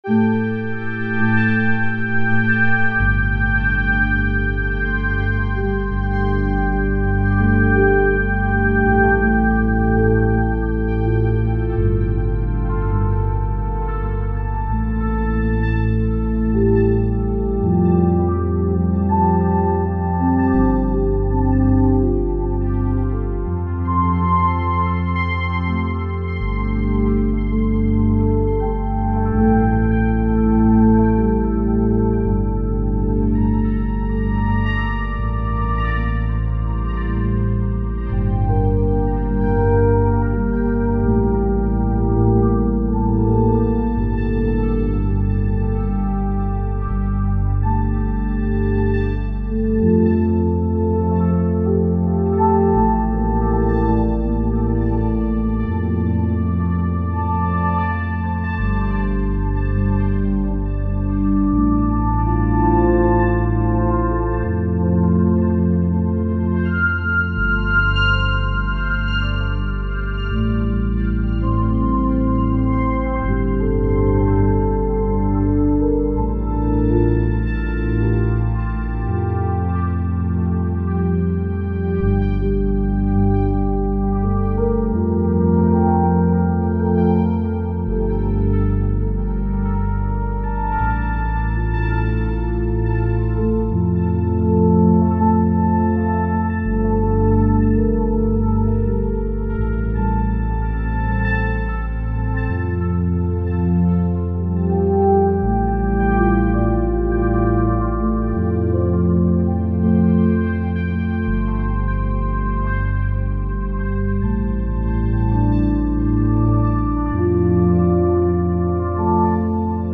Brumenn est un duo piano électrique/flute alors que nijal est un morceau ambient/New age.
Henon ( 1.7 - 0.6) Sol (G) Dorien 82